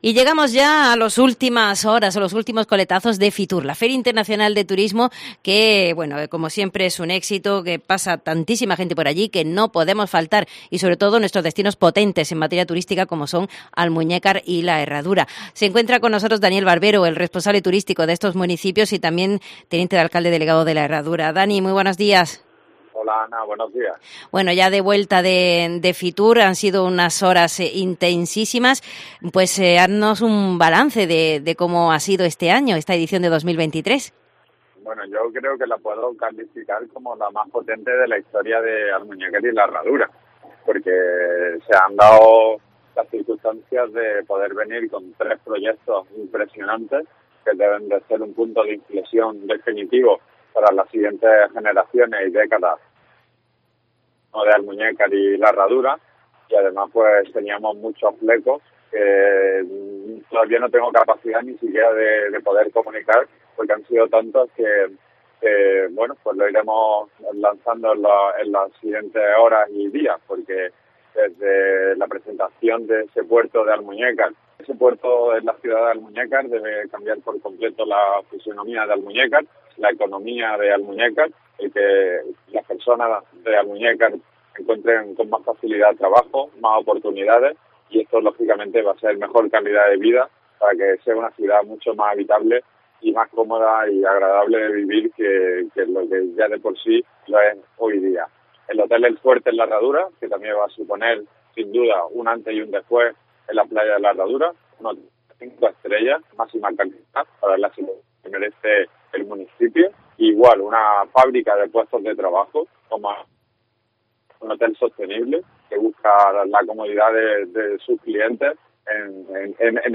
Hablamos con Daniel Barbero, Teniente de Alcalde de Turismo y Playas de Almuñécar y Teniente de Alcalde Delegado de La Herradura y hacemos balance con el de este edición de FITUR, donde el destino turístico Almuñécar La Herradura ha destacado sobremanera, sobre todo con tres grandes proyectos como el Hotel de 5 estrellas de la Cadena El Fuerte, que podría estar funcionando en La Herradura en 2027, El Parque Azul de Vida Submarina, para lo que ya hay presupuesto y a finales de año podría estar cerrado ya el tema administrativo y El nuevo Puerto Deportivo, en plena ciudad de Almuñécar, que también lleva los trámites muy avanzados.